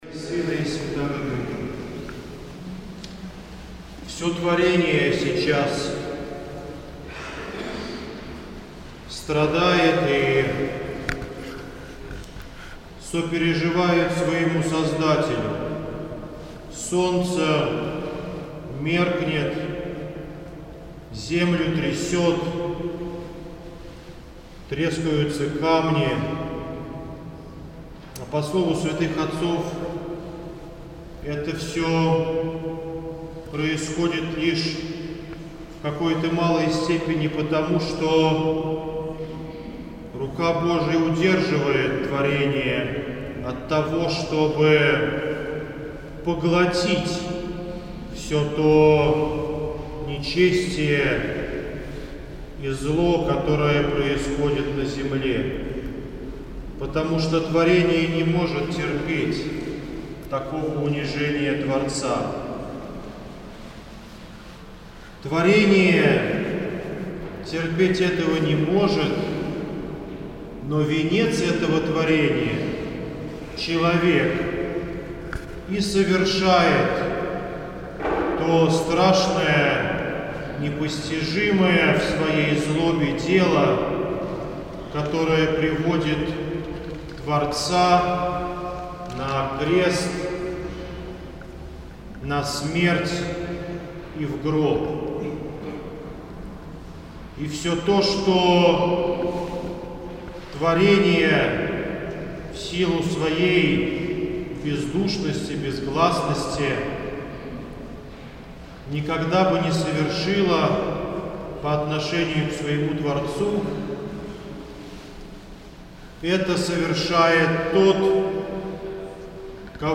Слово в Великую Пятницу